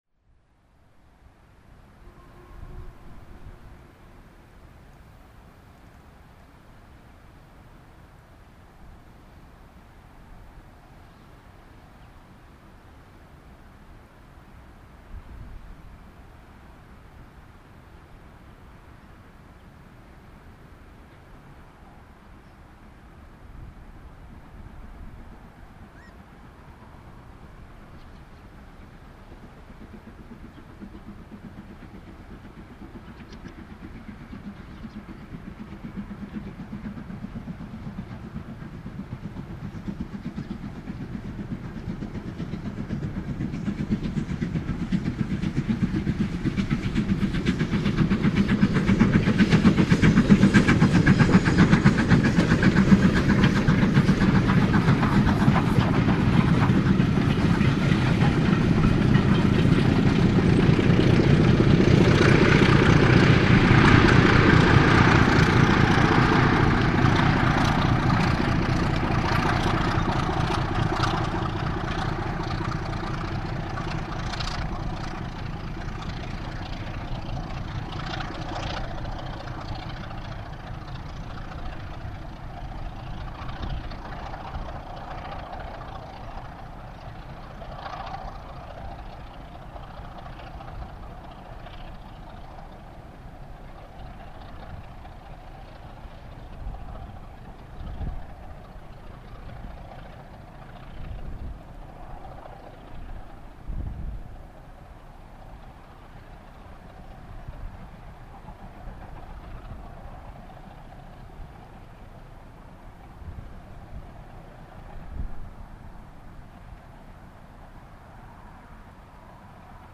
I'd previously made a half decent recording of Southern S15 825 at this spot,  so was hoping for some good noise, but again, the diesel was helping a bit, so 45212 is only lightly working  the train as it comes past.